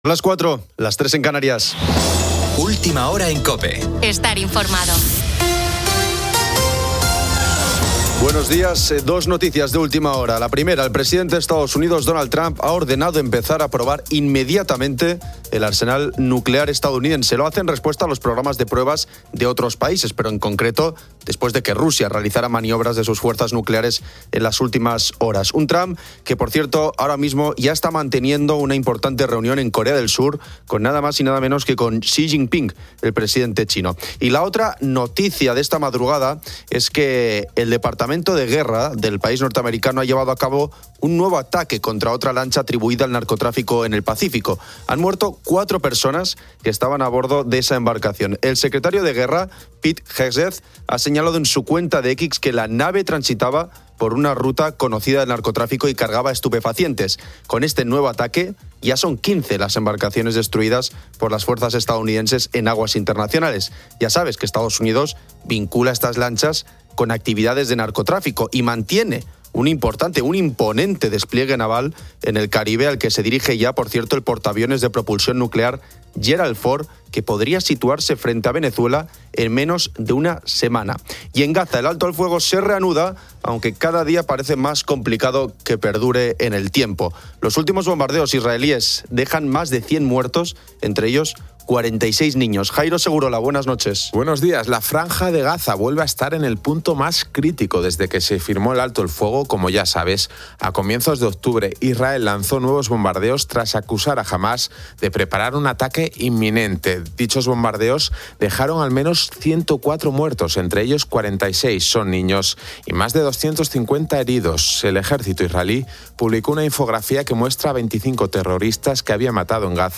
El programa "PONIENDO LAS CALLES" de COPE aborda hallazgos de tesoros y turnos de noche, incluyendo una entrevista con un arqueólogo subacuático sobre un barco español hundido y mensajes de oyentes.